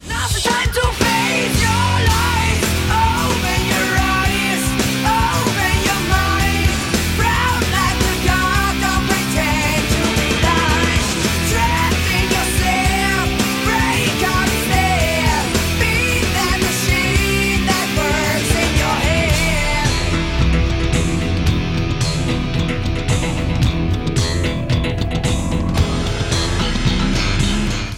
женский вокал
Alternative Rock